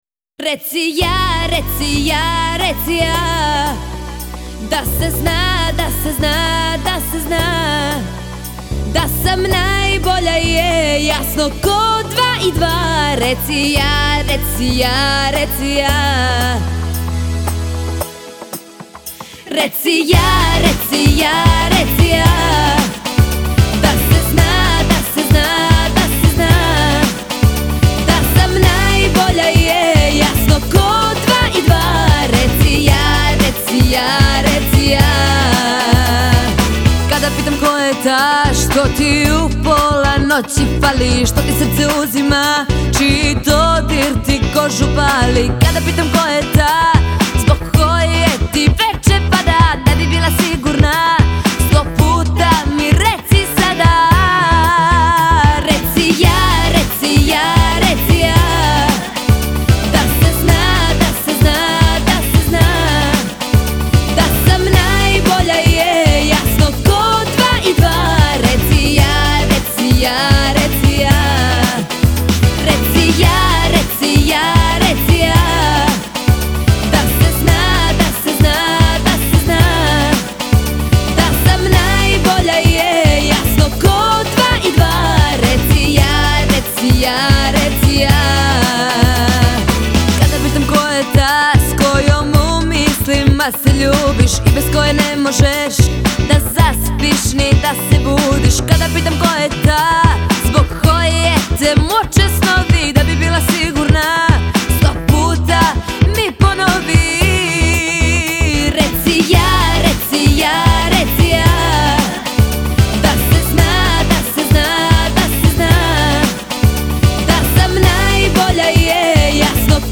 Для любителей современной сербской эстрадной музыки.